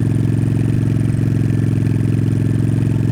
Engine_loop_5.ogg